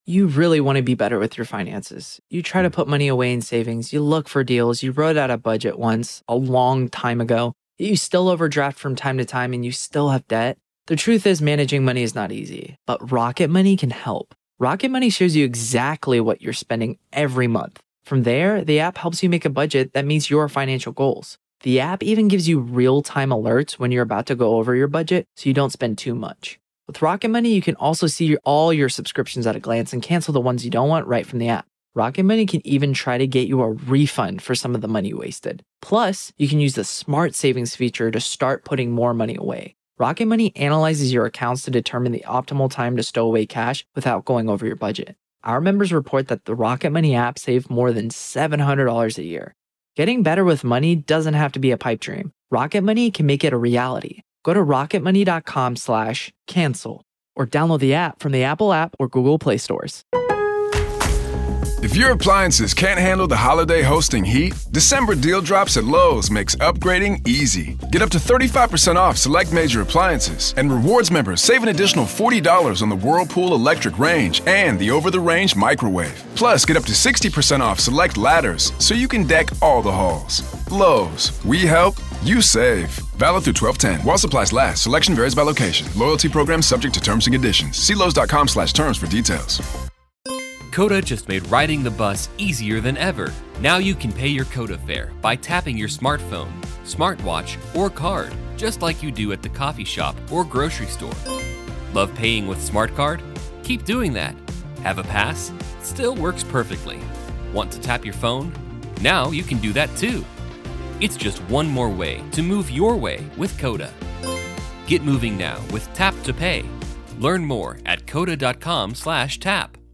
A podcast where two homo comedians talk about life, love and culture... sometimes.